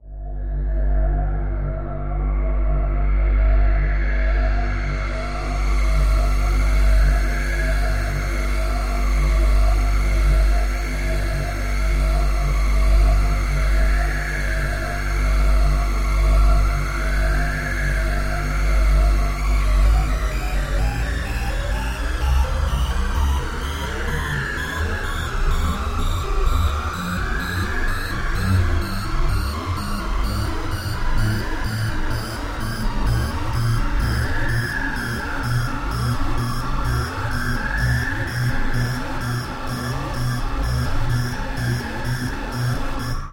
Звук потери управления внутри кабины космического корабля (включена тревога) (00:43)
включена тревога